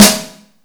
kits/RZA/Snares/GVD_snr (30).wav at main
GVD_snr (30).wav